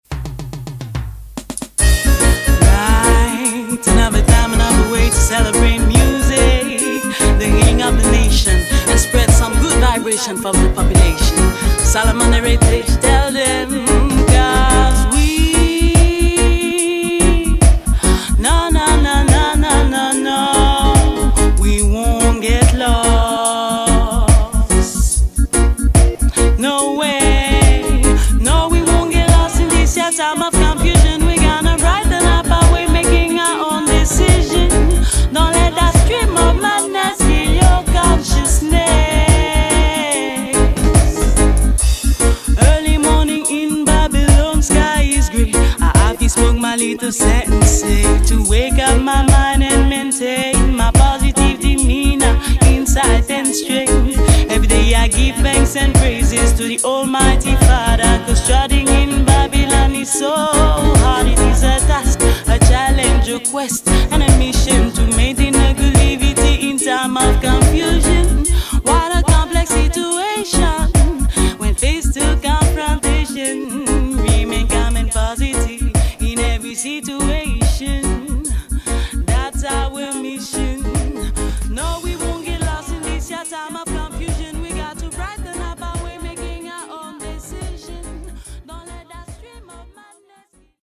dubplate